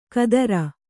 ♪ kadara